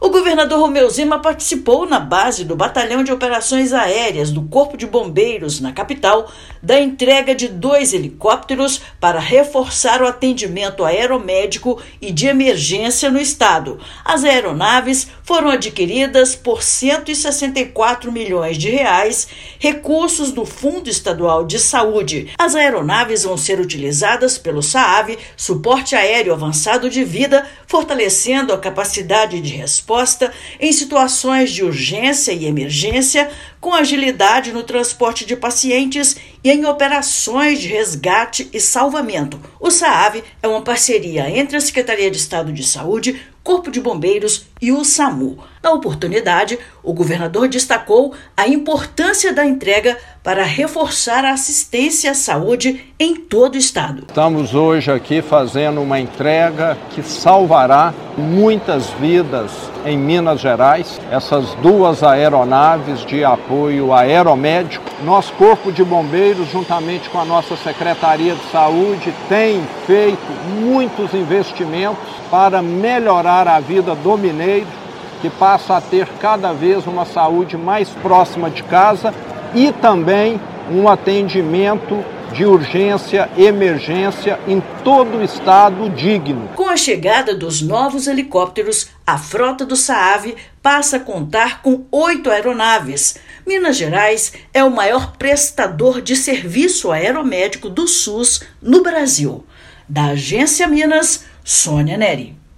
Reforço do Suporte Aéreo Avançado de Vida (Saav) garante mais agilidade no transporte de pacientes e operações de resgate e salvamento. Ouça matéria de rádio.